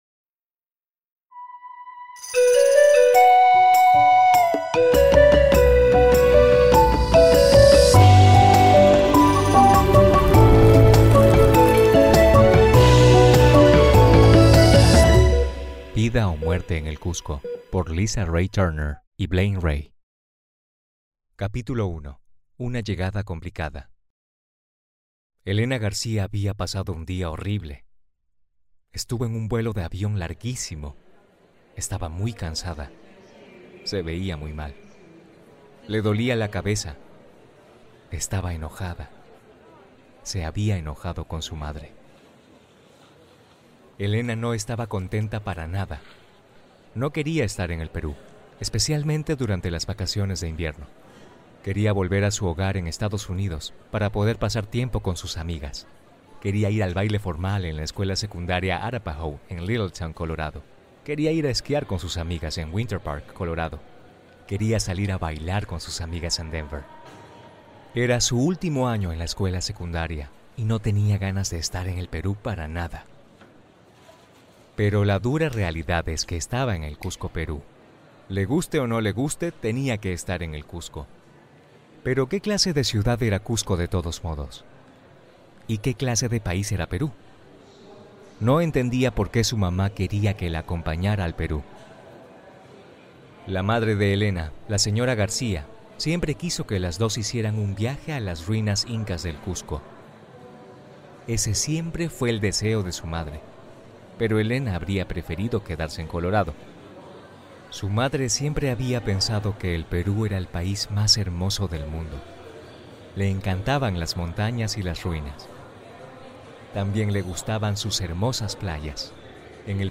Each audio book contains original music, sound effects and voice acting from Native Spanish speakers to ensure a memorable experience for your students!
Vida o muerte en el Cusco Audiobook sample: